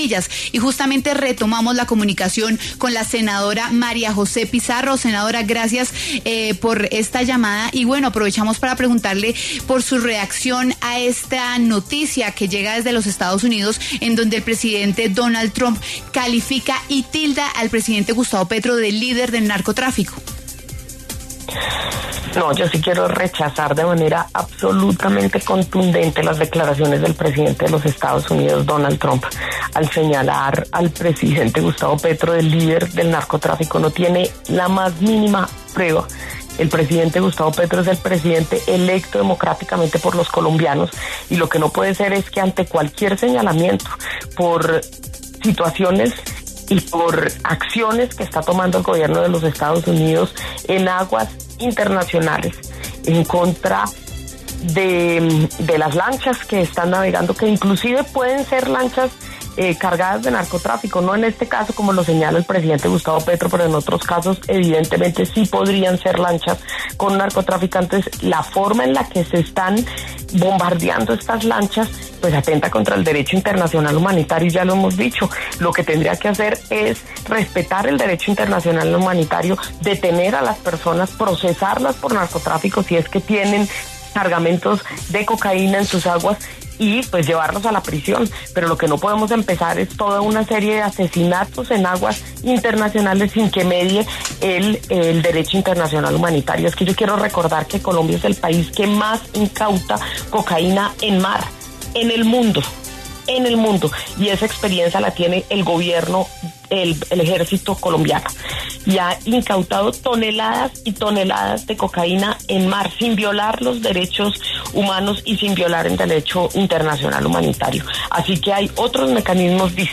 Los congresistas María José Pizarro, Cristian Garcés,Mauricio Gómez, Juana Carolina Londoño y Ariel Ávila hablaron en W Fin de Semana.